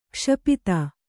♪ kṣapita